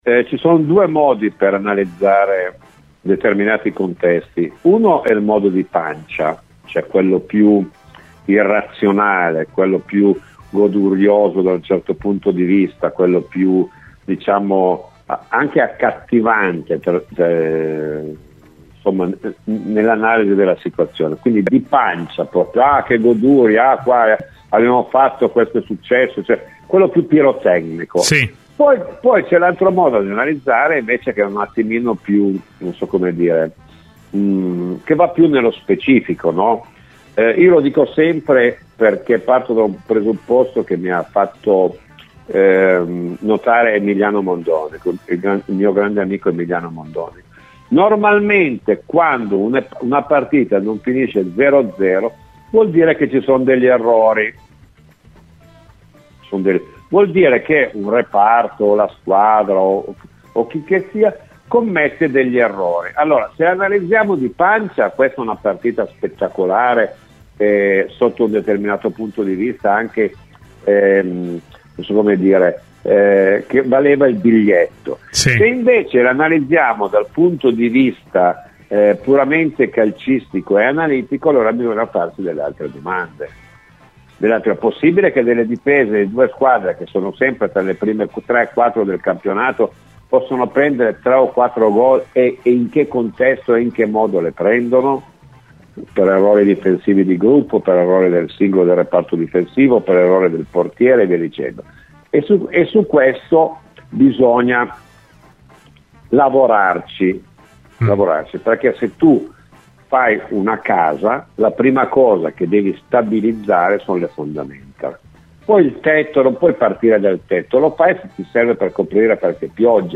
Ospite di "Cose di Calcio" su Radio Bianconera, Domenico Marocchino ha commentato il rocambolesco 4-3 dello Stadium tra Juventus ed Inter: "Che partita è stata?